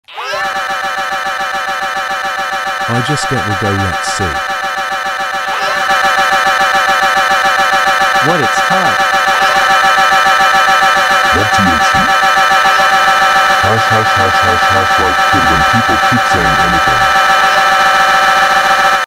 Wallet land pizza tower screaming sound effects free download